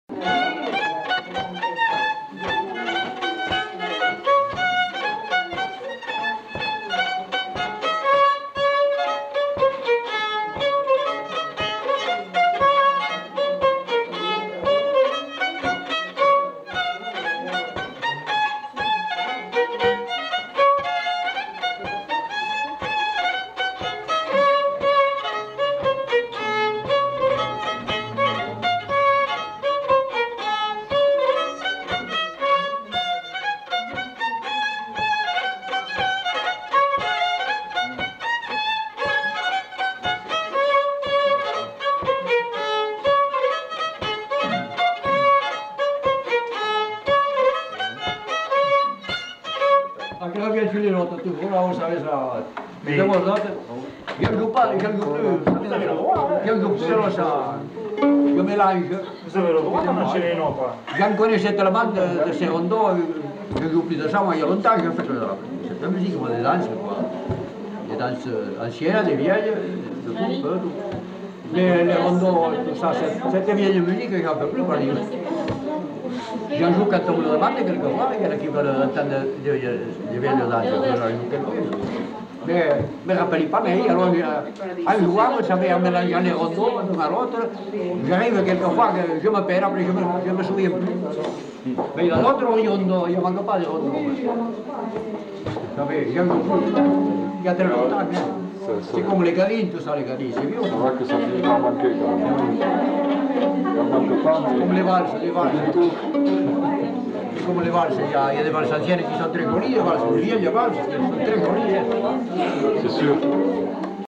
Lieu : Allons
Genre : morceau instrumental
Instrument de musique : violon
Danse : rondeau